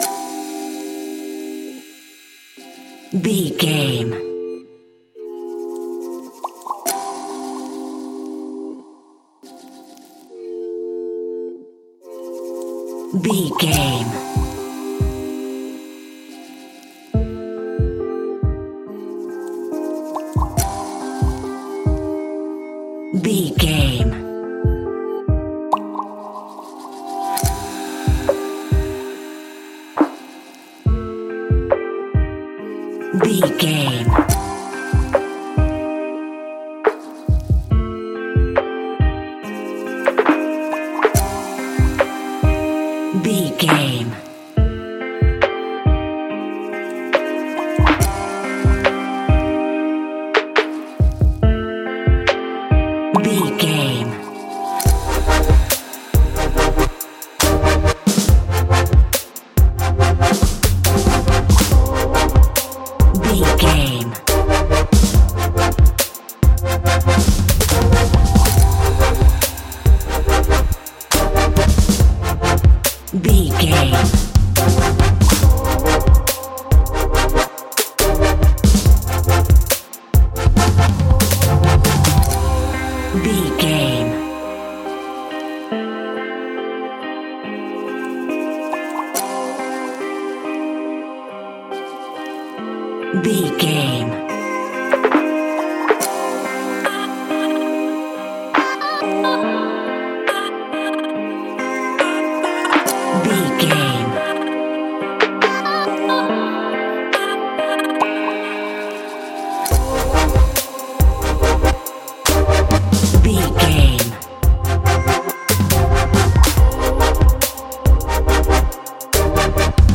Aeolian/Minor
Fast
electric guitar
synthesiser
drum machine